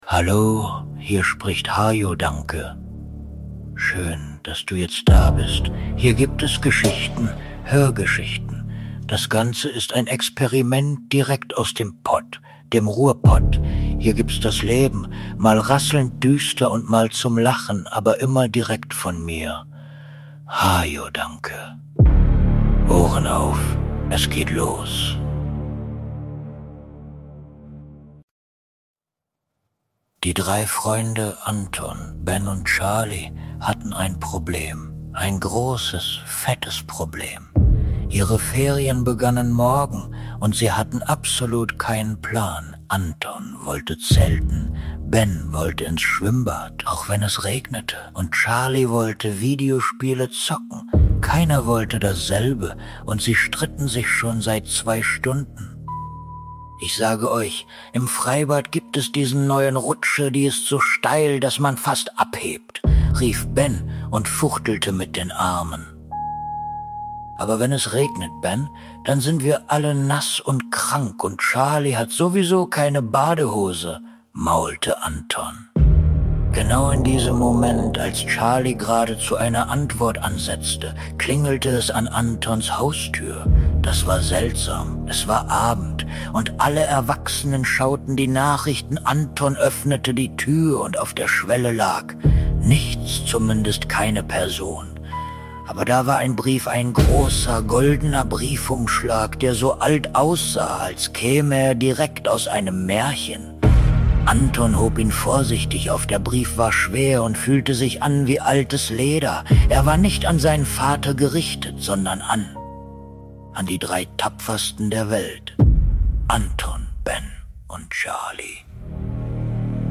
Eine spannende Grusel-Hörgeschichte für Kinder über Freundschaft, Schatten und magische Räts